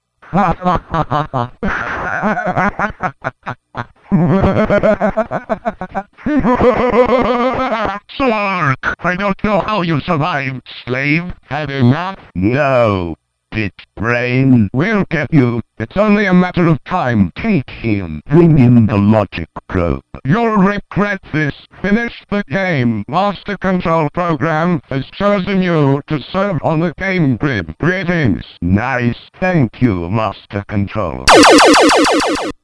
here to listen to what a DOT Squawk & Talk board does when the diagnostic button is pressed (6MB sound file!).
squawk.wav